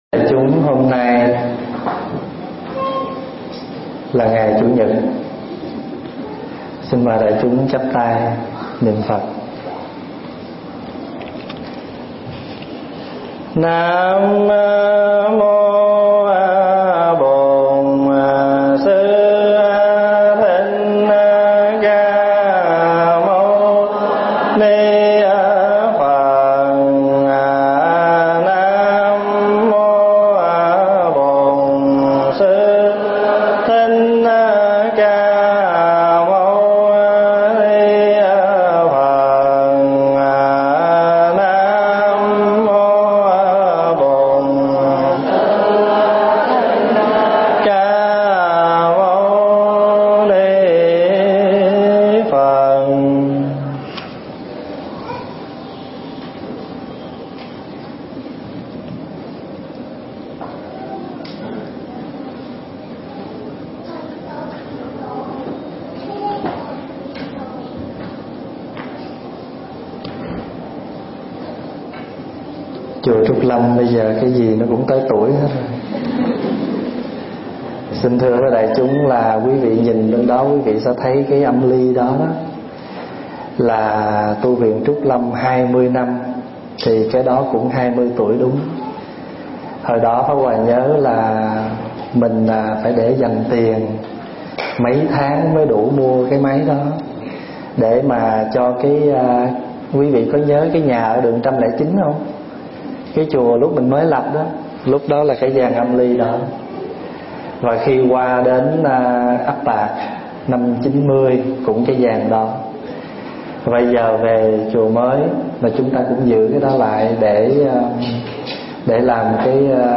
thuyết giảng tại Tu Viện Trúc Lâm, Canada, sinh hoạt phật pháp hằng tuần